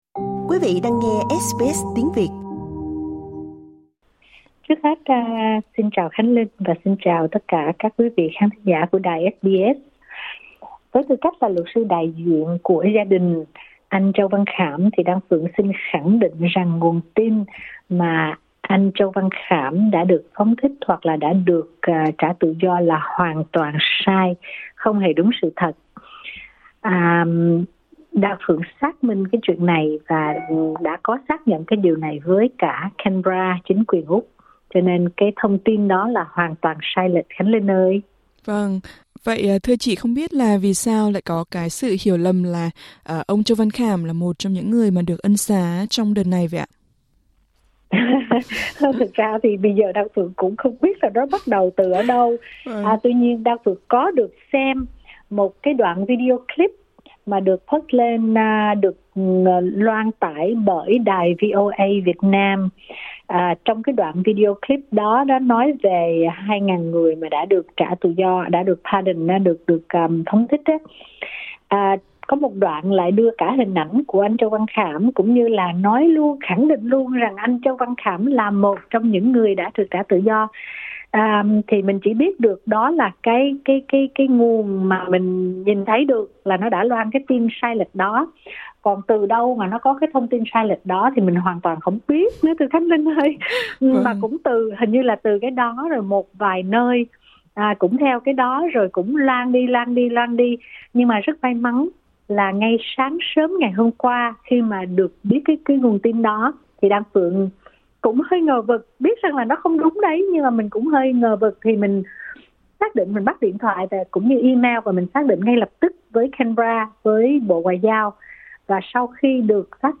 SBS Tiếng Việt trò chuyện nhanh với luật sư đại diện gia đình